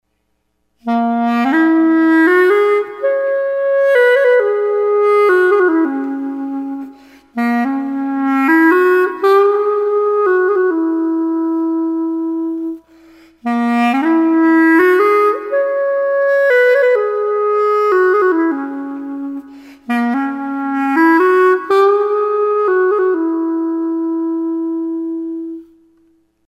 Зафун Bb. Именная серия (Signature Series - Bamboo Xaphoon in Bb)
Именная серия (Signature Series - Bamboo Xaphoon in Bb) Тональность: Bb
Зафун (xaphoon) - духовой музыкальный инструмент сочетающий в себе звучание саксофона, портативность и простоту в освоении. Звук извлекается при помощи саксофонной трости закрепленной на трубке с 9-ю игровыми отверстиями. Диапазон инструмента составляет две хроматические октавы.